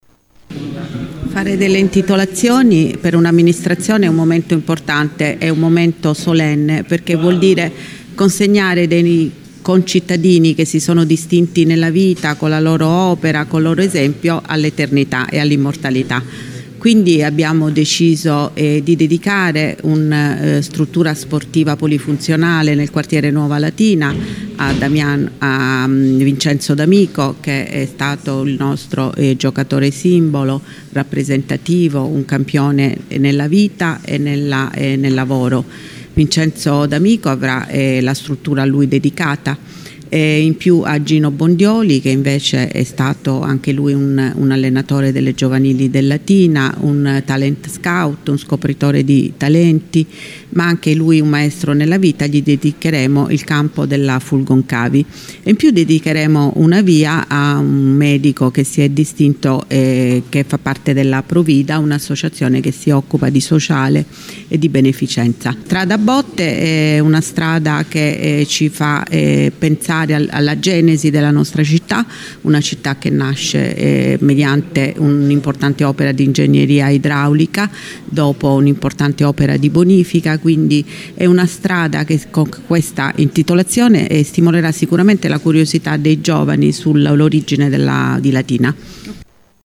sindaco-intitolazioni.mp3